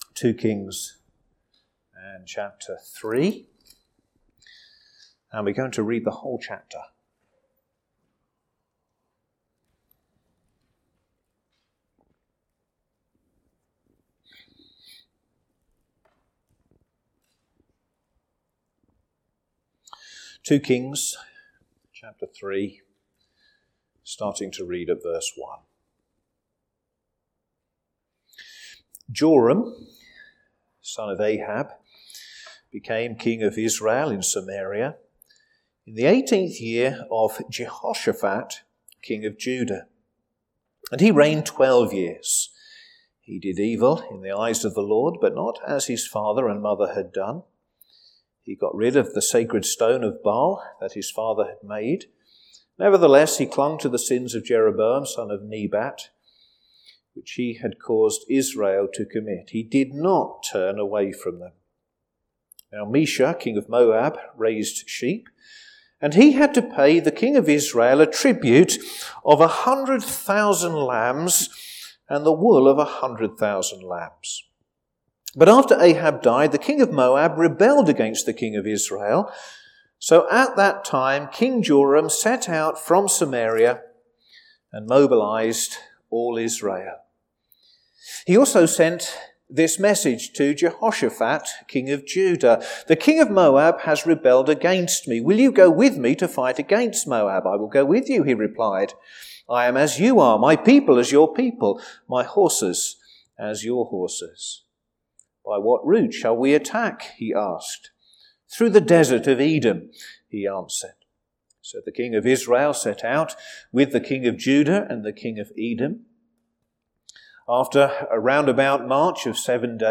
Home Christ Sermons Who's standing next to you?